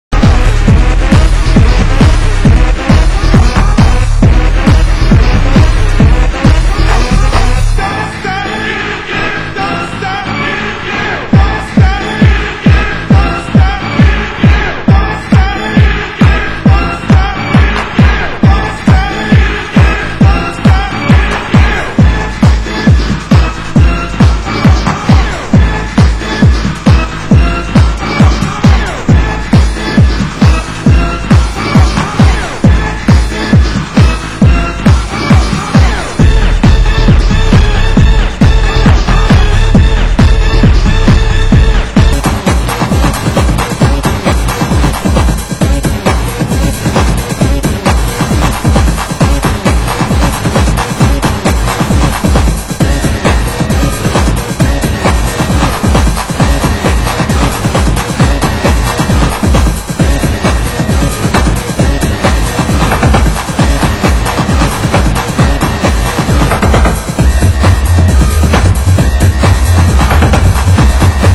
Format: Vinyl 12 Inch
Genre: US Techno